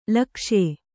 ♪ lakṣye